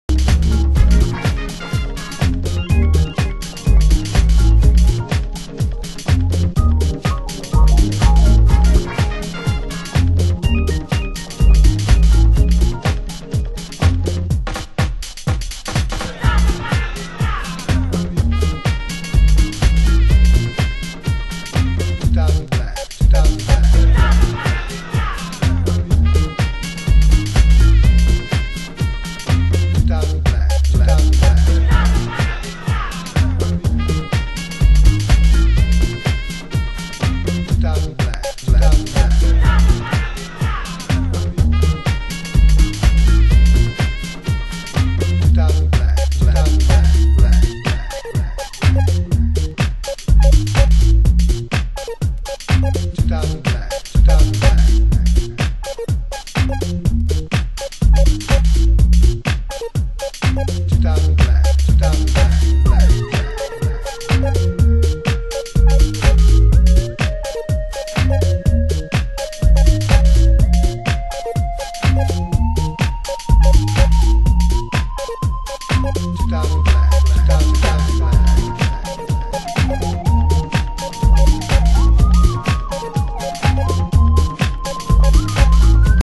HOUSE MUSIC
盤質：少しチリパチノイズ有